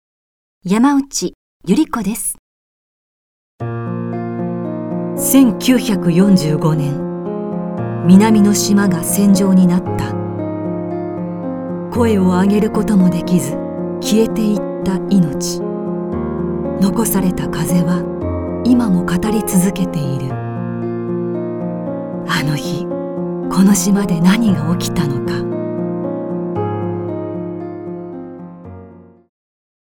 ナレーション